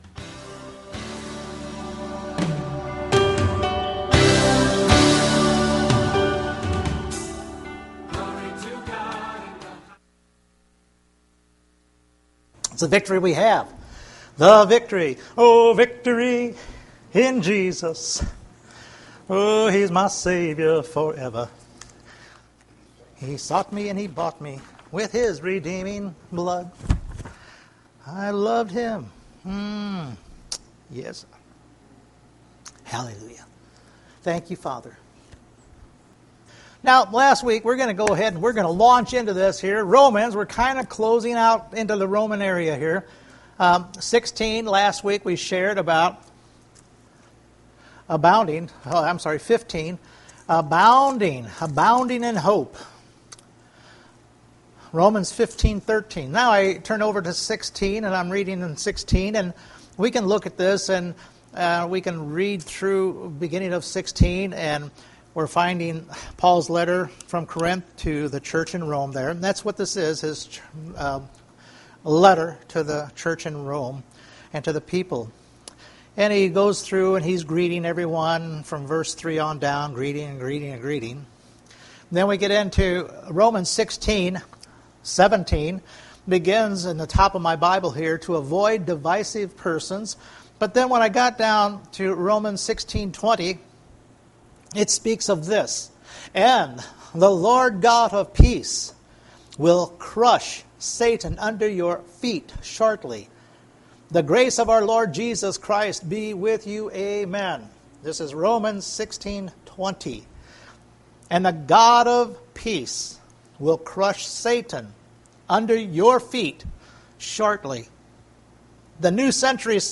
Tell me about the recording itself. Romans 16:20 Service Type: Sunday Morning In the book of Romans we find that God will crunch Satan under your feet!